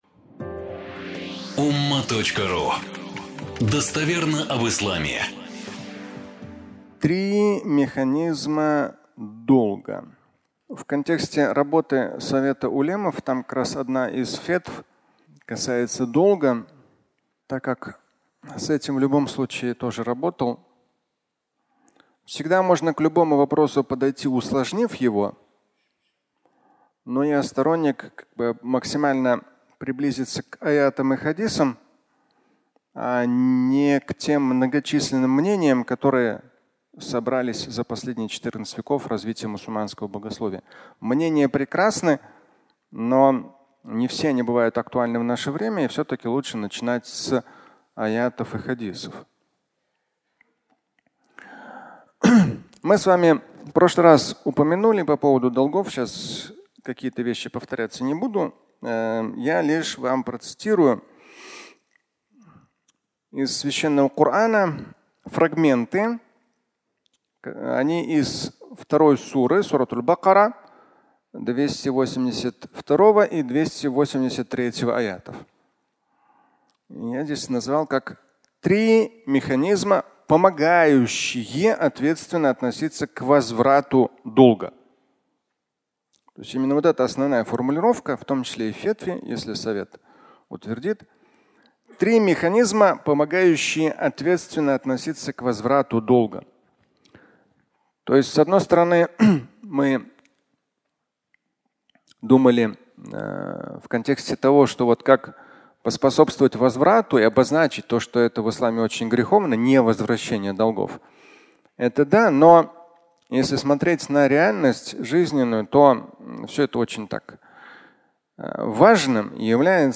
Три механизма возврата долг (аудиолекция)
Фрагмент пятничной лекции